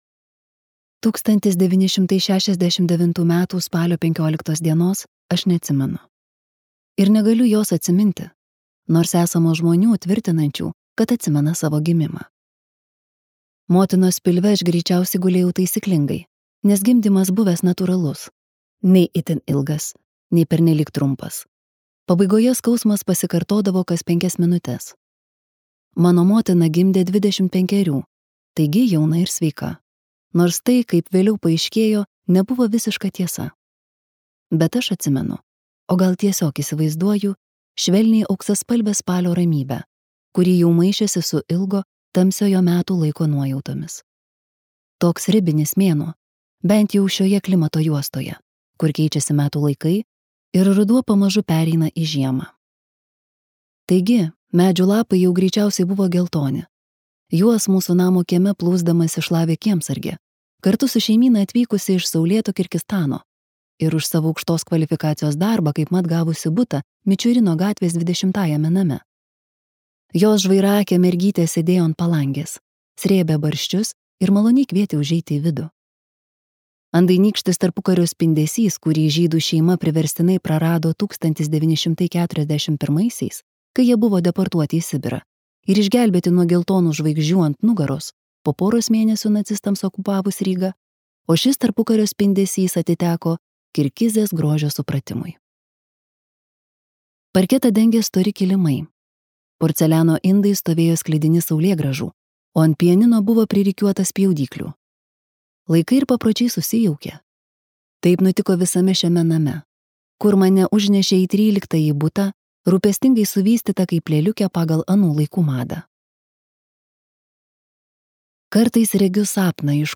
Audio Motinos pienas